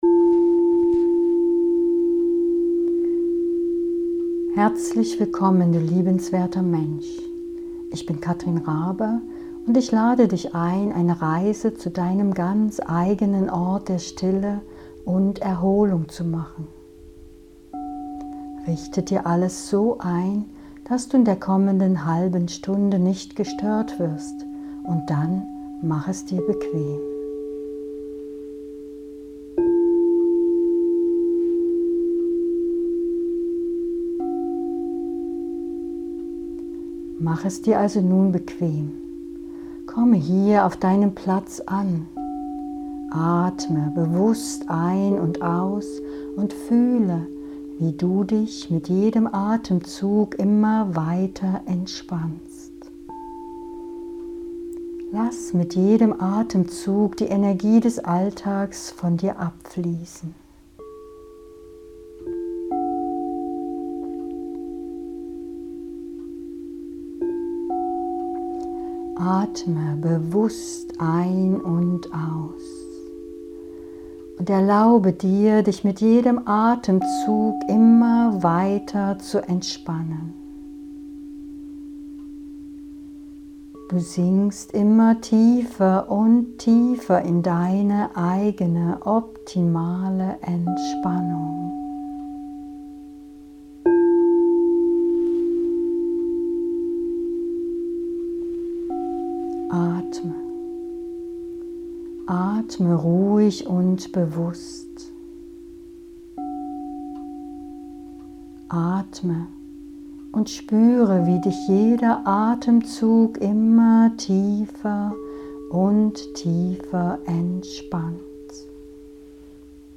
Eine geführte Meditation begleitet von Kristall- Klangschalen, 432 Hz
meditation-ort-der-stille-mp3.mp3